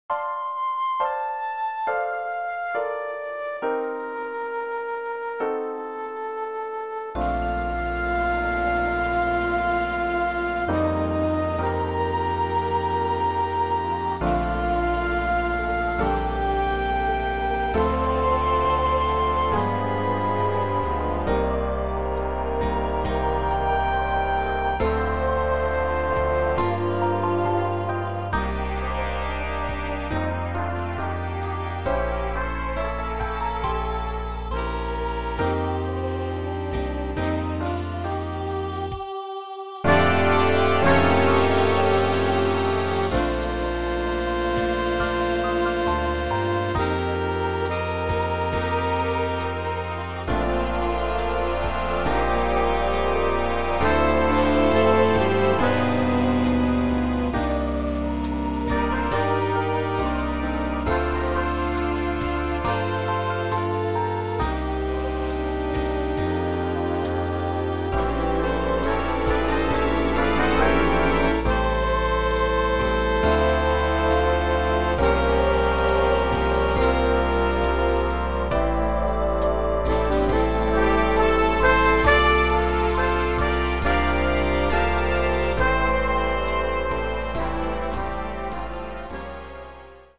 Jazz Band
big band adaptation
Instrumentation is 5 saxes, 6 brass, 4 rhythm.